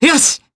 Fluss-Vox_Happy4_jp_b.wav